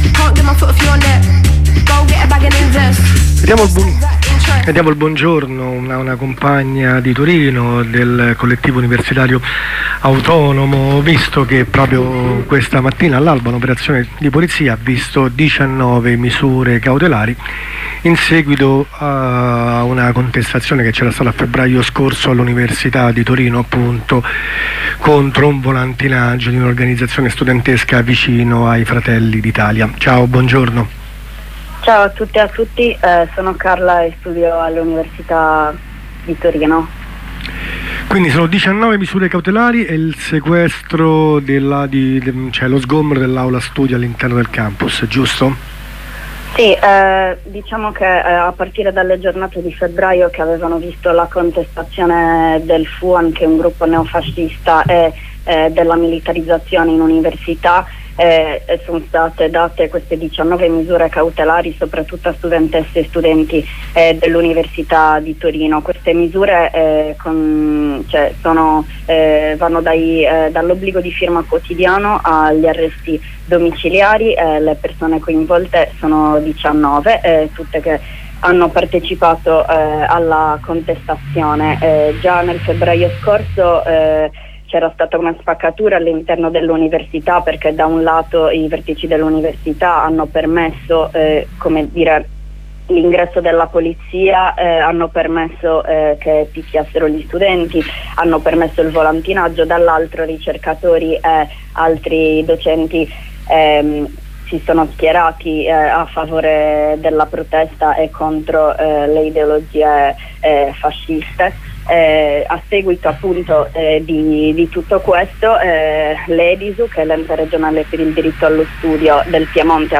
Gli agenti della Digos hanno notificato 19 misure cautelari a compagne e compagni del CUA che lo scorso inverno avevano cercato di impedire un volantinaggio del Fuan al Campus Einaudi. Ne parliamo con una compagna.